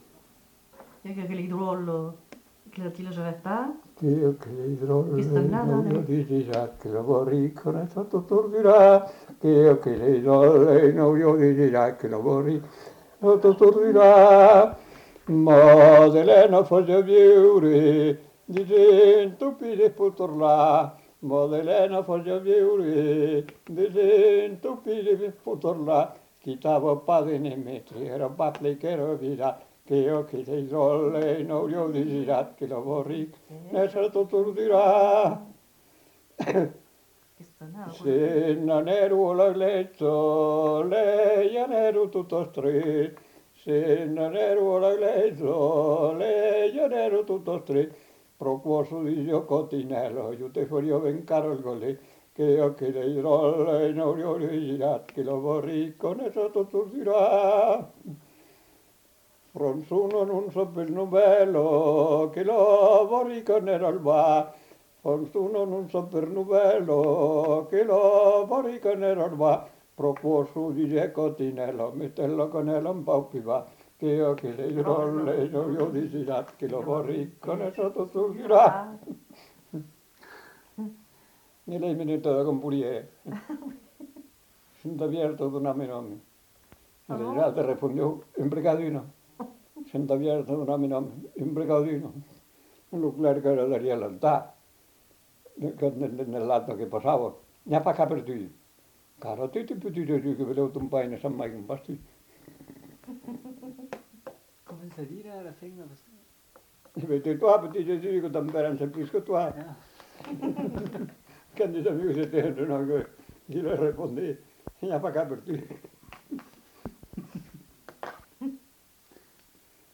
Lieu : Lacroix-Barrez
Genre : chant
Effectif : 1
Type de voix : voix d'homme
Production du son : chanté
Description de l'item : fragment ; 3 c. ; refr.